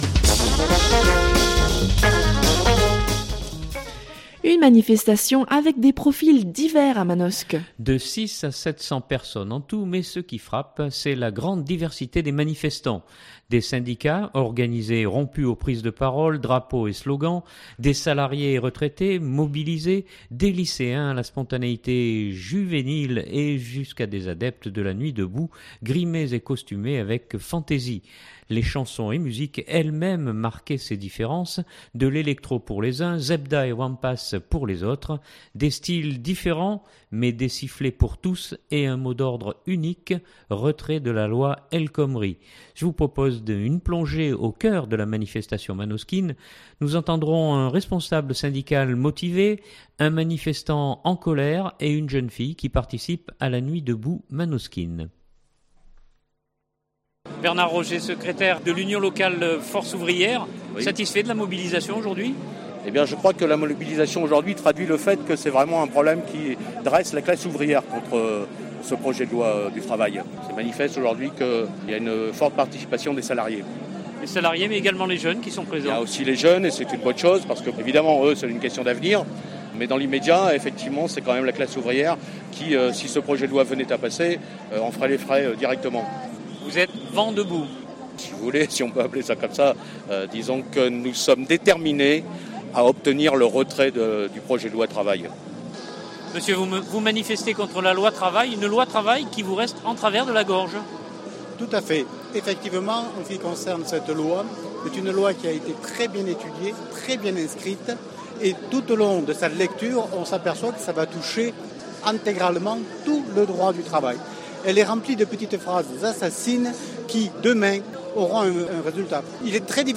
Manosque manifestation.mp3 (2.81 Mo)